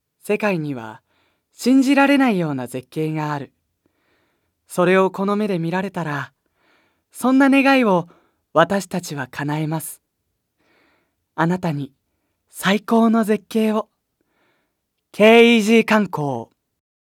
●ボイスサンプル５